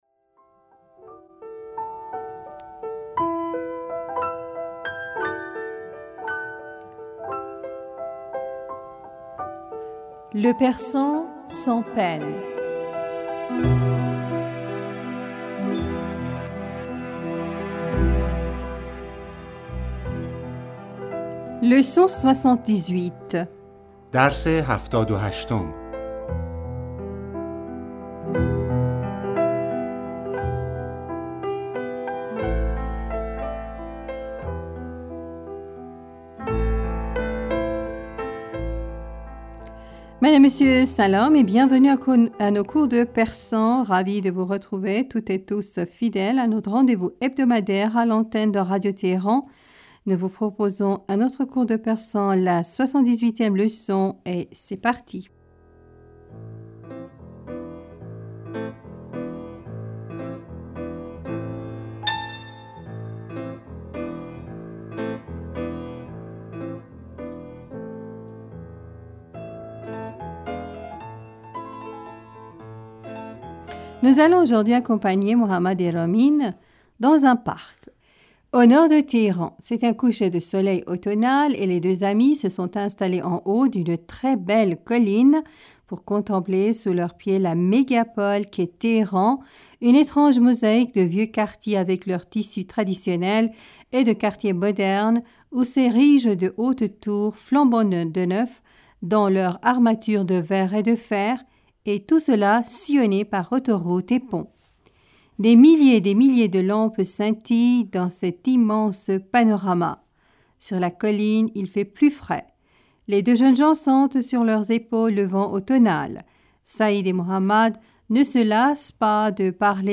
Madame, Monsieur Salam et bienvenus à nos cours de persan.
Ecoutez et répétez après nous.